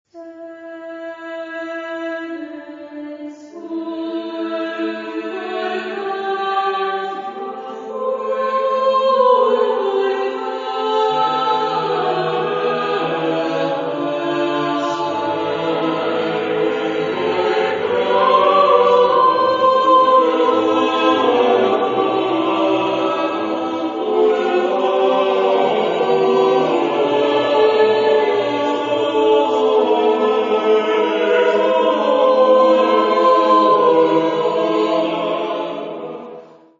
Genre-Stil-Form: Motette ; geistlich ; Renaissance ; Barock
Chorgattung: SATB  (4-stimmiger gemischter Chor )